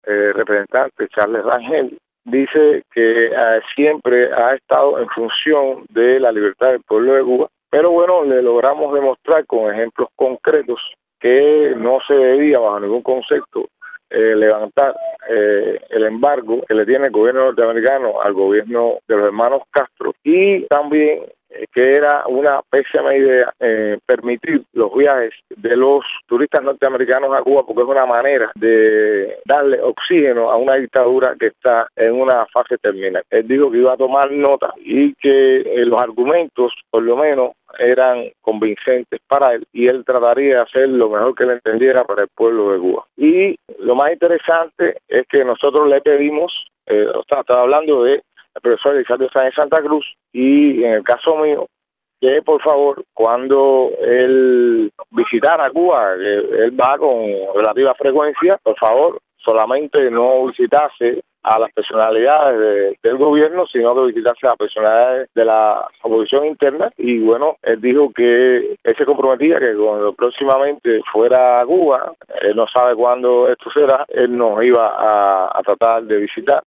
Declaraciones de Guillermo Fariñas sobre su encuentro con el congresista, Charles Rangel